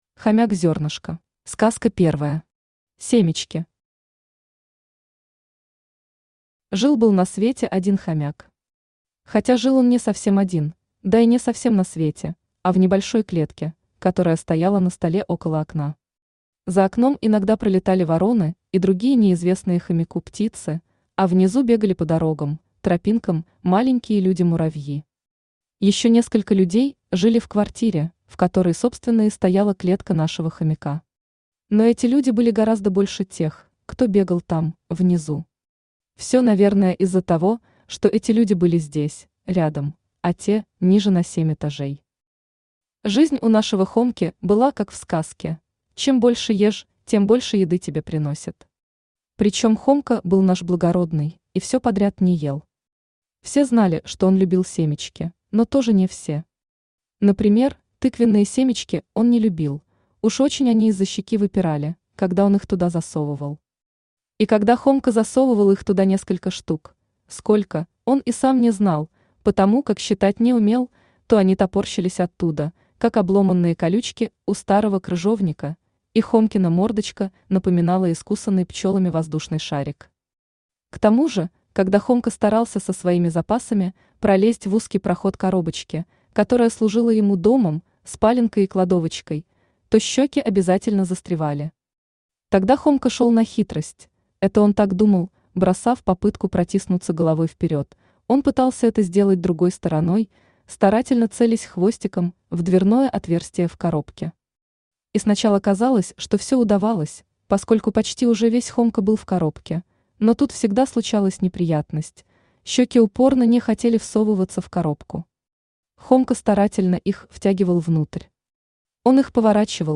Аудиокнига Хомяк Зернышко | Библиотека аудиокниг
Aудиокнига Хомяк Зернышко Автор Людмила Рыжова Читает аудиокнигу Авточтец ЛитРес.